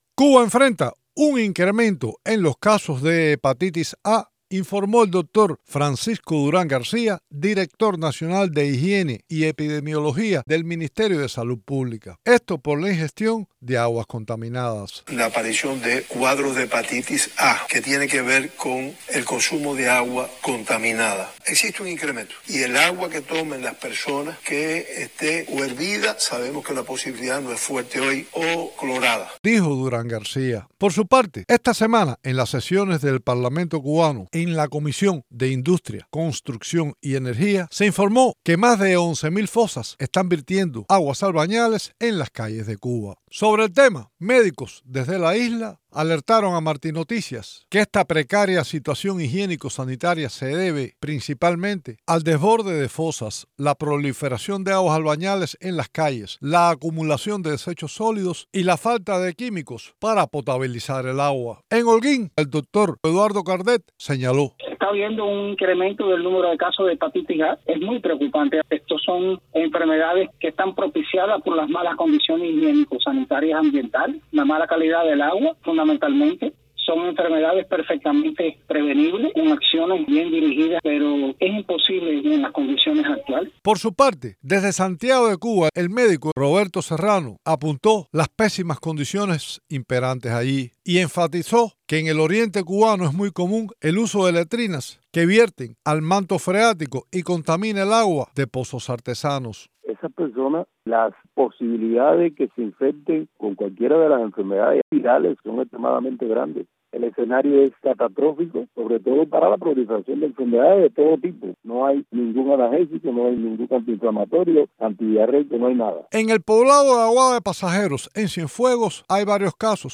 Las autoridade informaron de un brote de hepatitis A debido a la contaminación de las aguas. Cubanos consultados por Martí Noticias explican cómo las fosas están desbordadas.